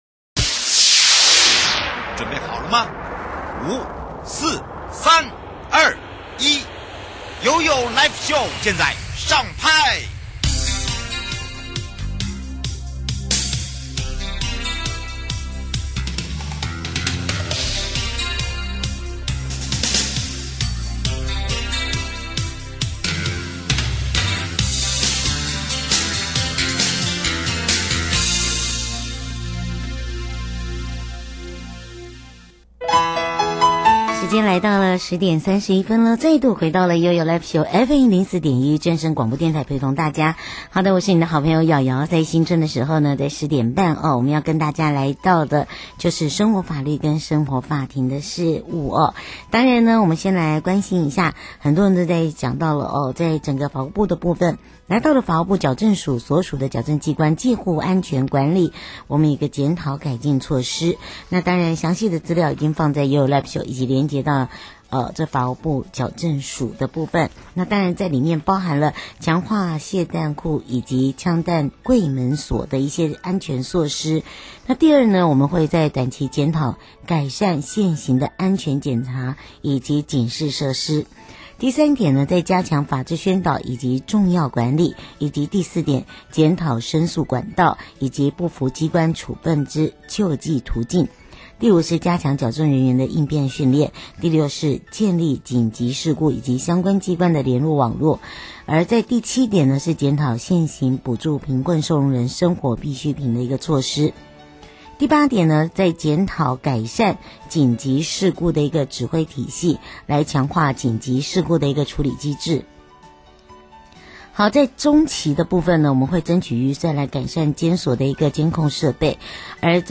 受訪者： 台北地檢江林達主任檢察官 節目內容： 老闆解雇員工的問題依照勞基法的規定,員工有什麼權利